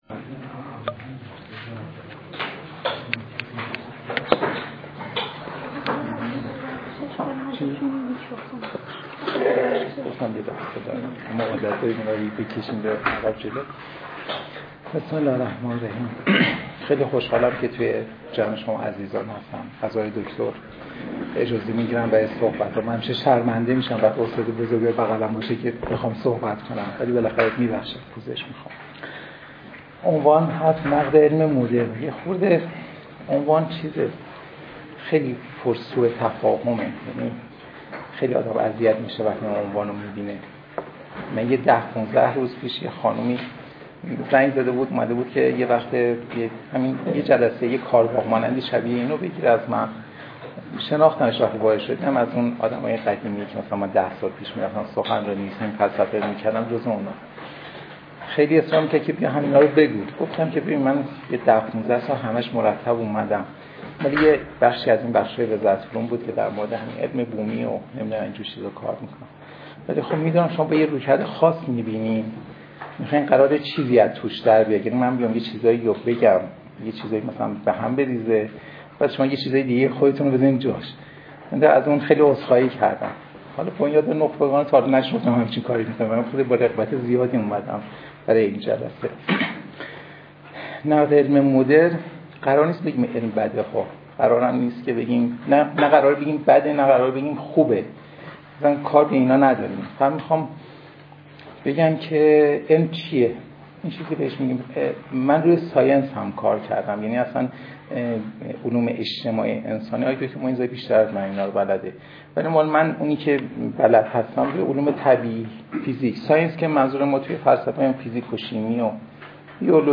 کارگاه آموزشی